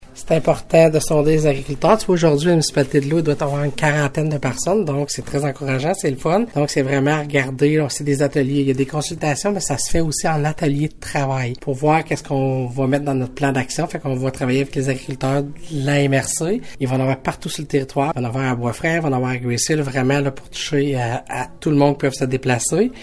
Ainsi, des consultations publiques chapeautées par la MRCVG ont débuté afin d’élaborer un plan d’action dans le but d’assurer une pérennité aux exploitations agricoles comme le précise la préfète de la MRCVG, Chantal Lamarche :